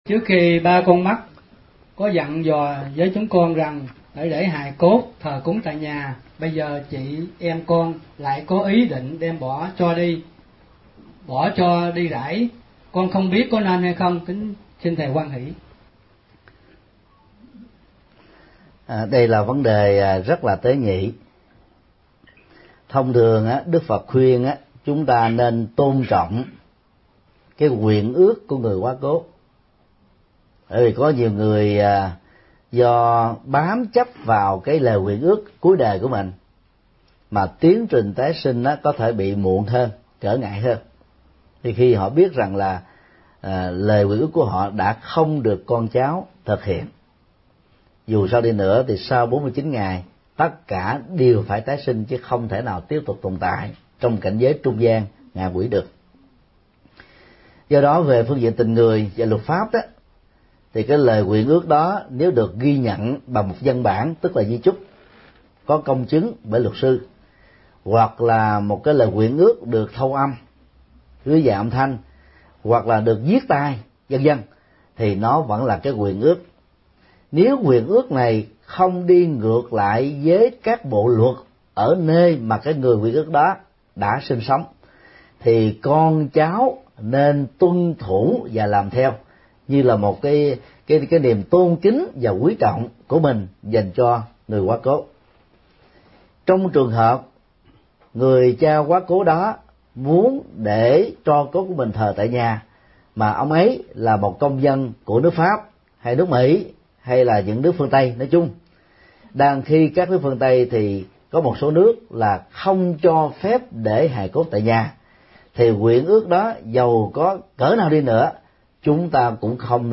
Vấn đáp: Có nên thờ hài cốt tại nhà